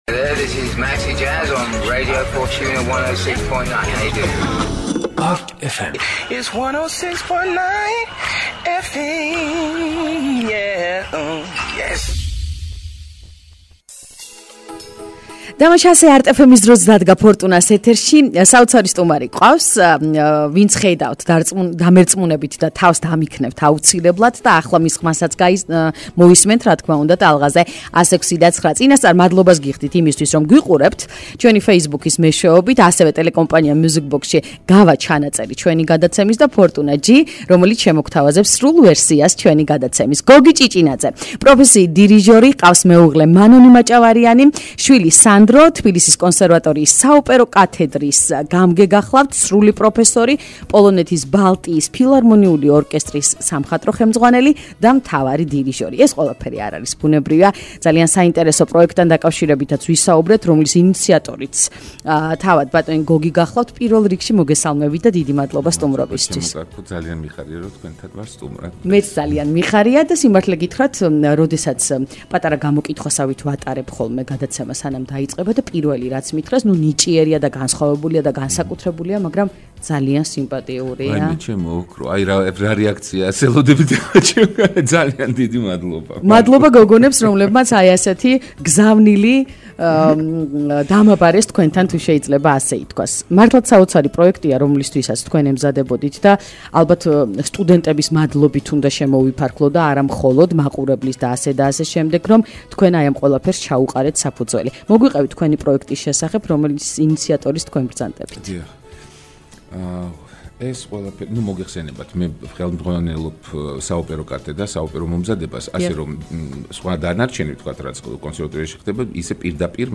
გადაცემის ლაივი LIVE